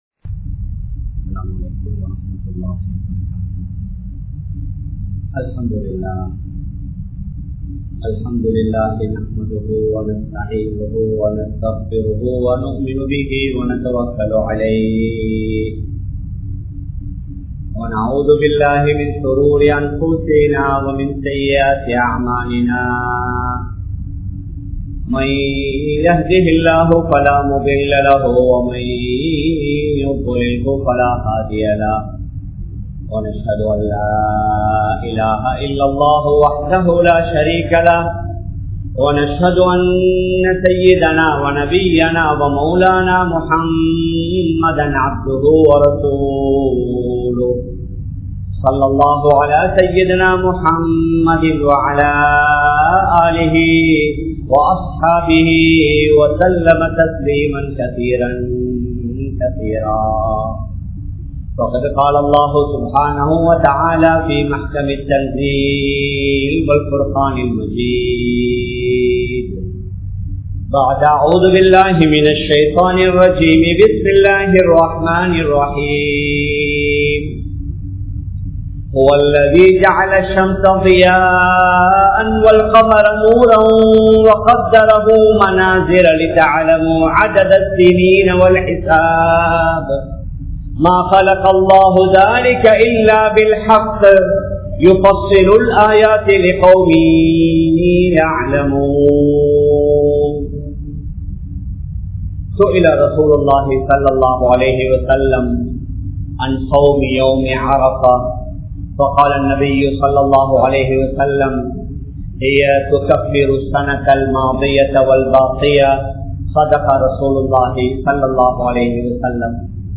Eppoathu Arafa Thinam & Hajj Perunaal Thinam SL`il? (இலங்கையில் எப்போது அரபா தினம், ஹஜ் பெருநாள் தினம்?) | Audio Bayans | All Ceylon Muslim Youth Community | Addalaichenai
Colombo 03, Kollupitty Jumua Masjith